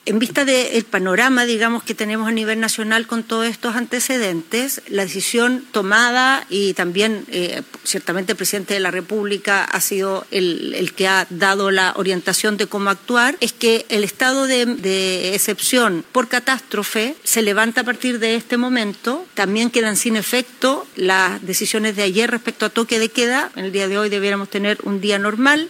CUNA-MINISTRA.mp3